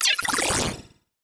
Space UI Touch and Reaction 4.wav